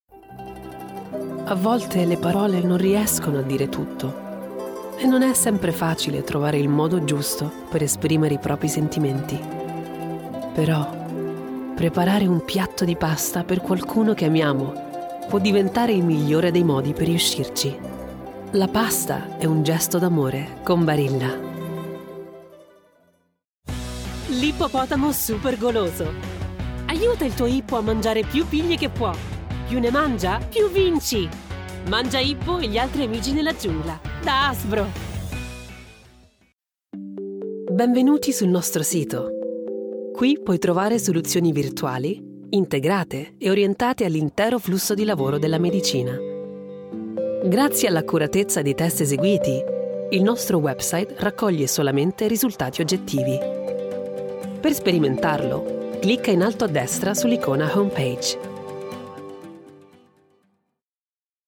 Showreel